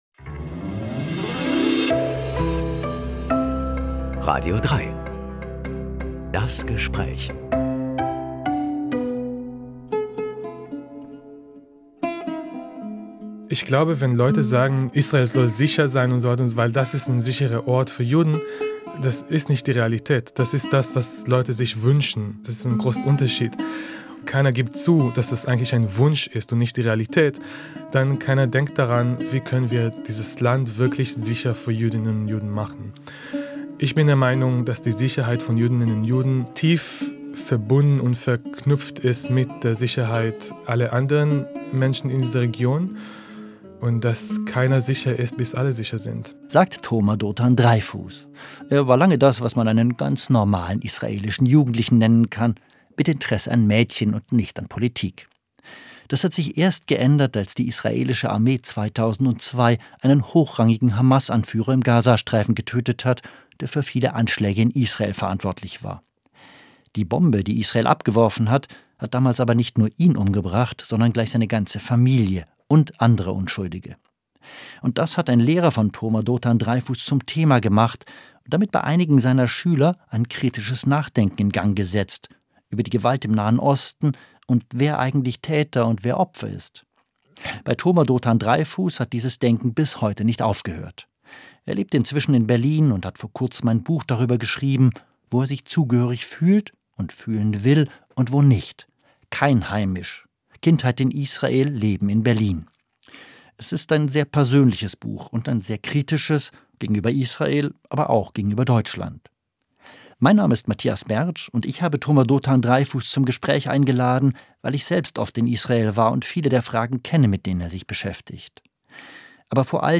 Schriftsteller ~ Das Gespräch Podcast